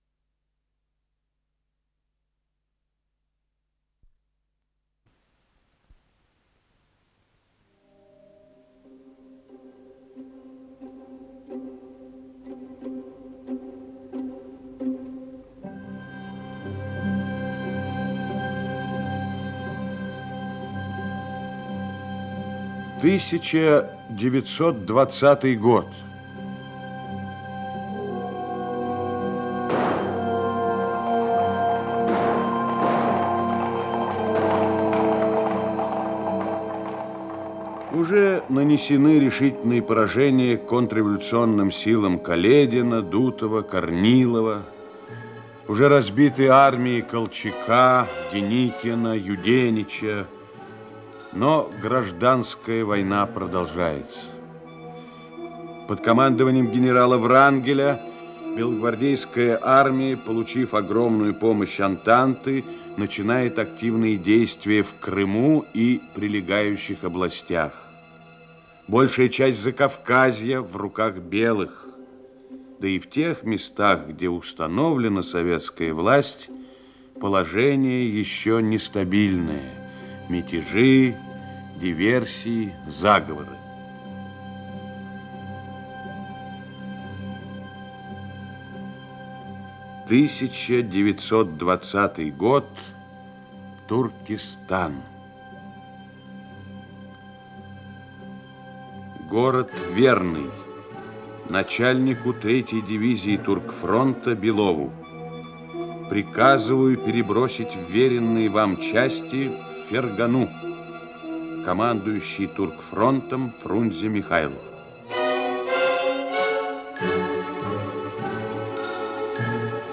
Исполнитель: Артисты МХАТ СССР им. Горького
Радиокомпозиция спектакля